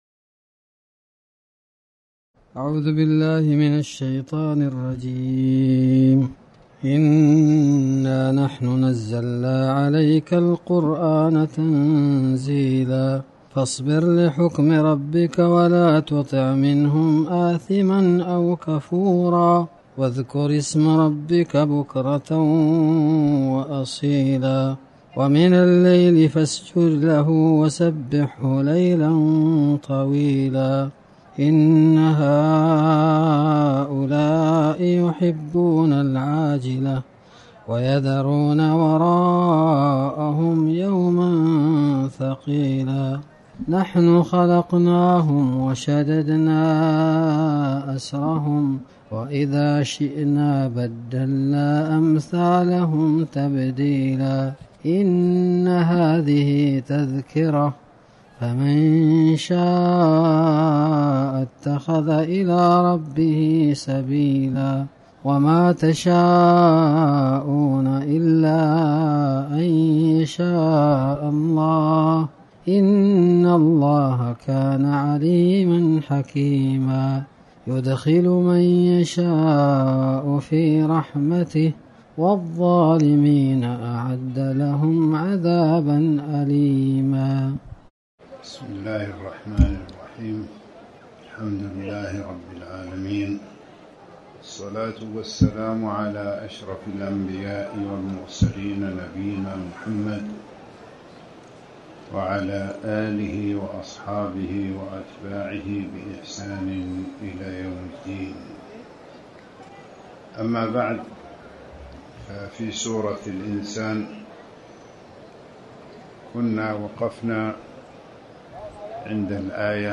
تاريخ النشر ١٣ صفر ١٤٤٠ هـ المكان: المسجد الحرام الشيخ